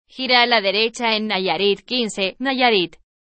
Entidad Federativa Prefijo Ejemplo TTS (Ariane)